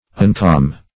Uncalm \Un*calm"\